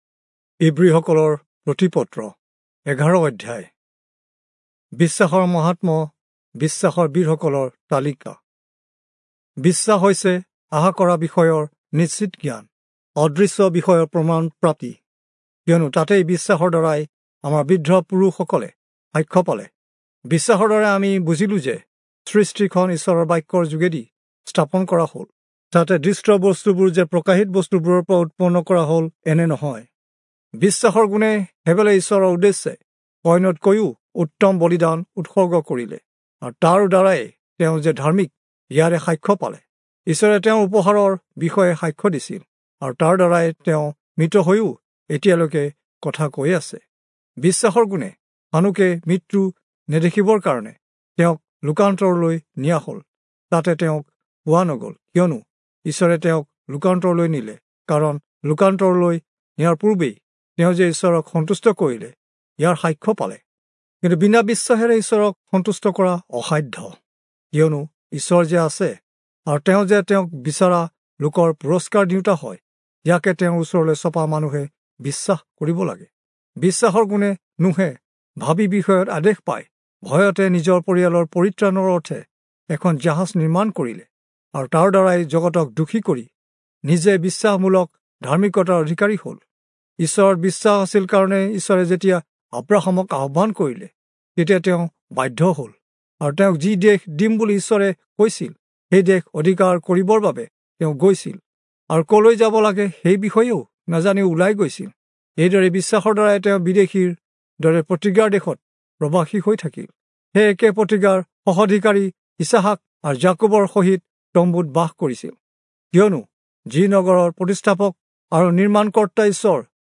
Assamese Audio Bible - Hebrews 7 in Lxxen bible version